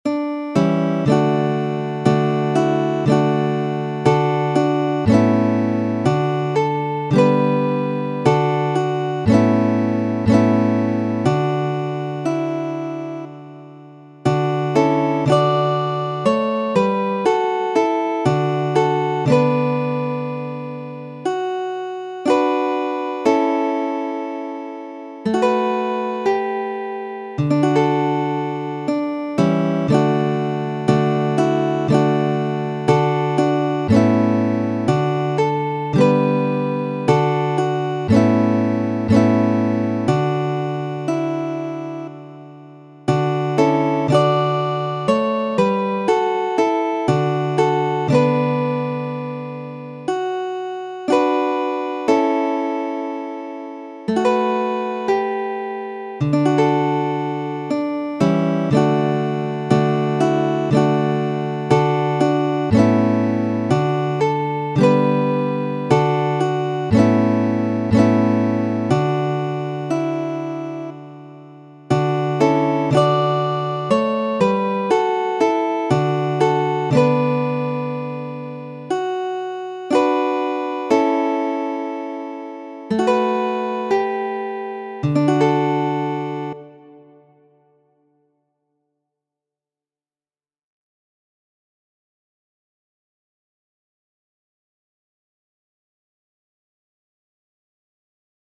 Oro Kvina, oomota kanto, en gitara versio, tiel, kiel mi ludis en la lasta Universala Kongreso.